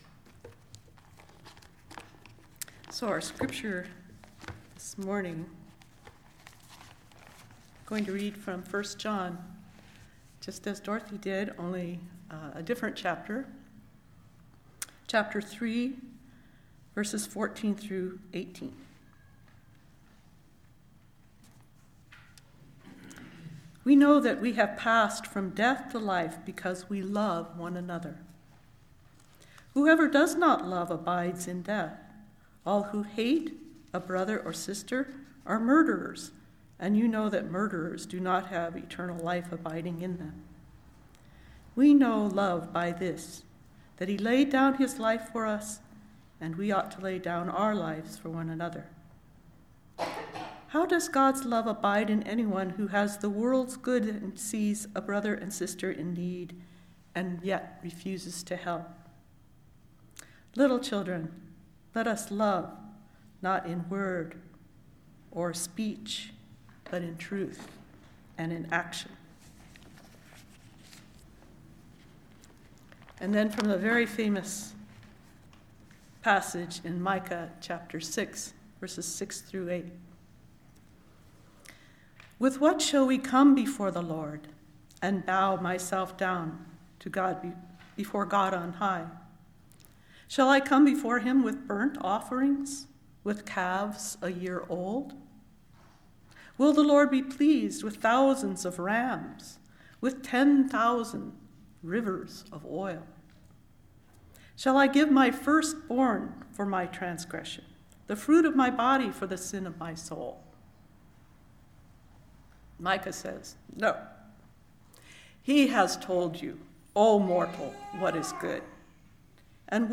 Listen to the most recent message from Sunday worship at Berkeley Friends Church, “Hate.”